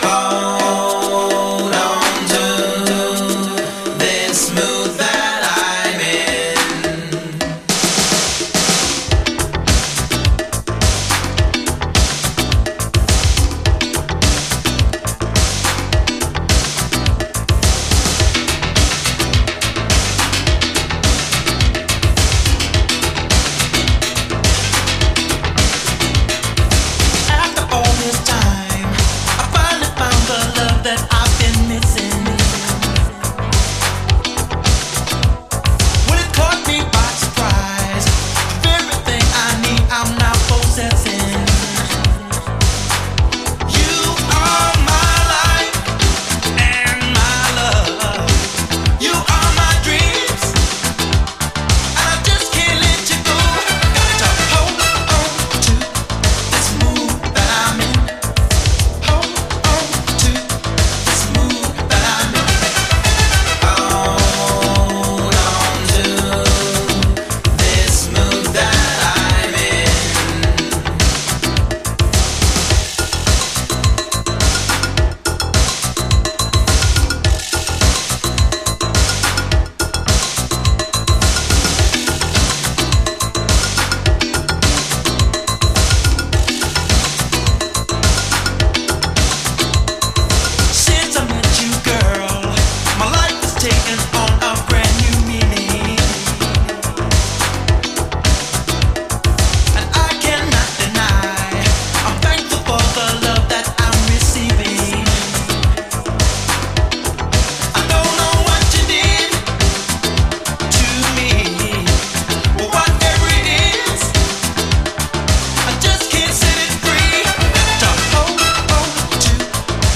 DISCO